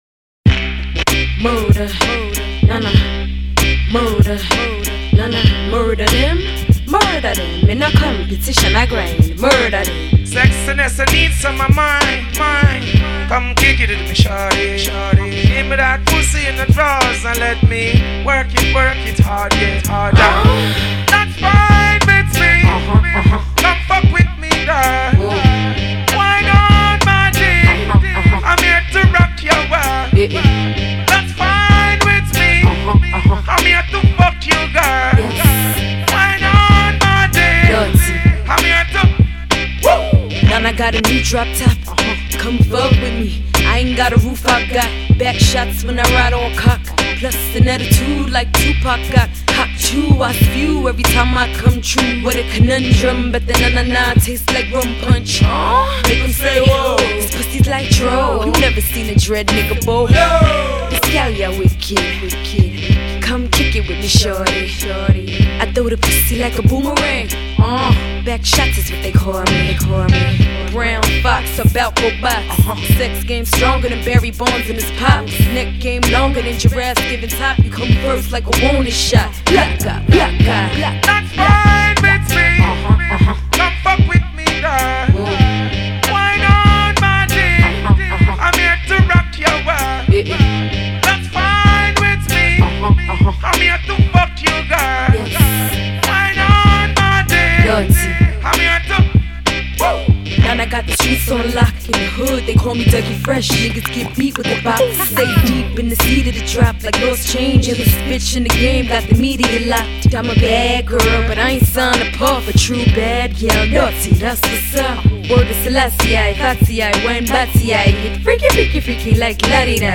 it's rock, dancehall, hip-hop
flirts with a reggae-rap hybrid